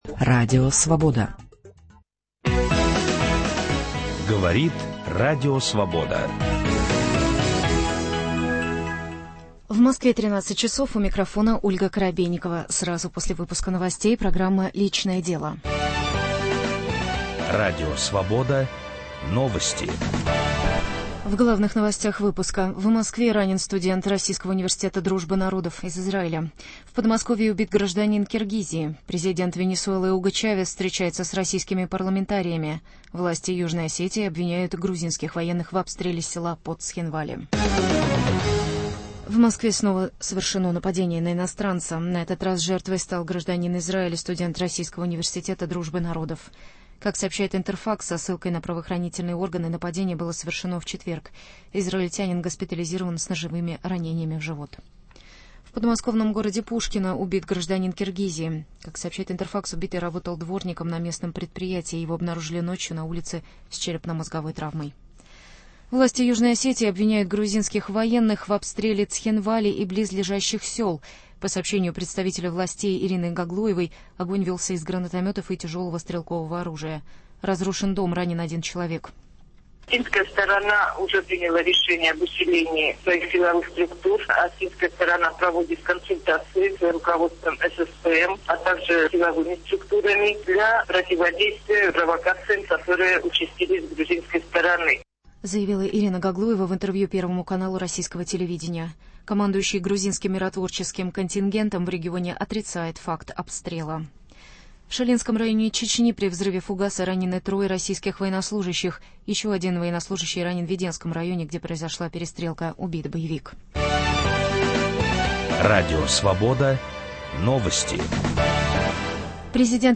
Гости программы - психолог, писатель